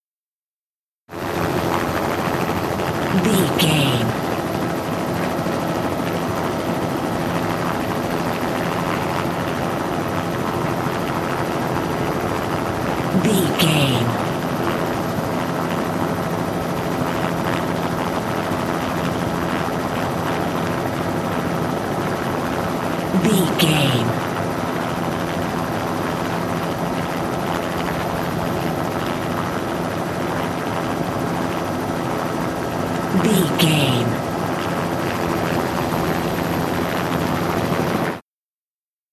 Helicopter fly idle ext
Sound Effects